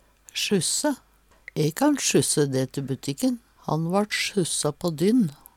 sjusse - Numedalsmål (en-US)